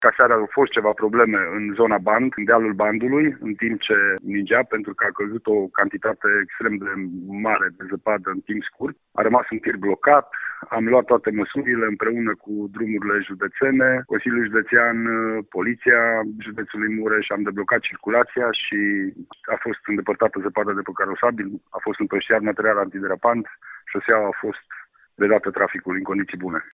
Revine Lucian Goga.